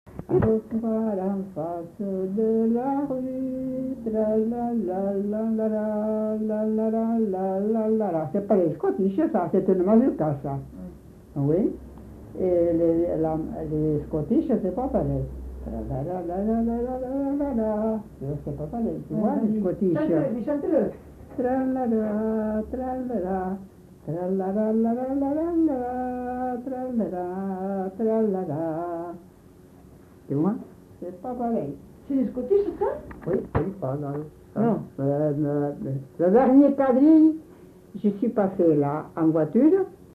Aire culturelle : Marmandais gascon
Lieu : Casteljaloux
Genre : chant
Effectif : 1
Type de voix : voix de femme
Production du son : fredonné
Danse : scottish
Notes consultables : En début de séquence, bribes d'un chant.